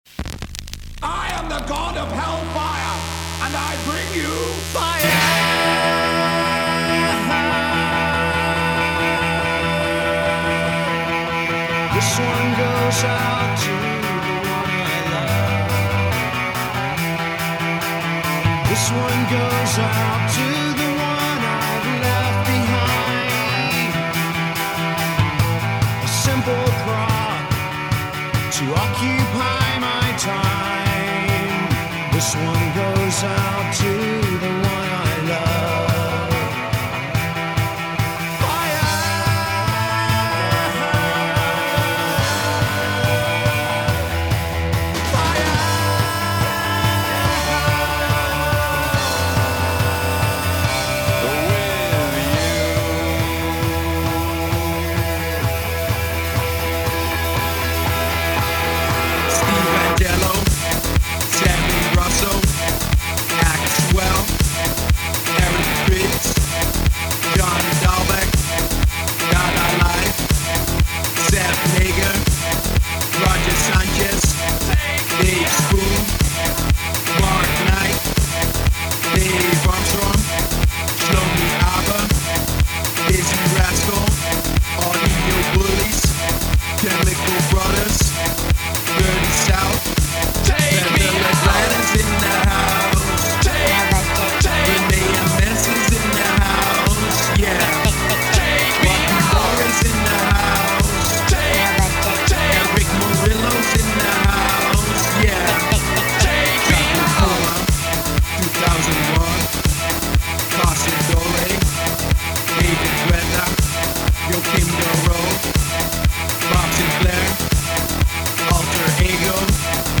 Acapella
Instrumentale
Extended version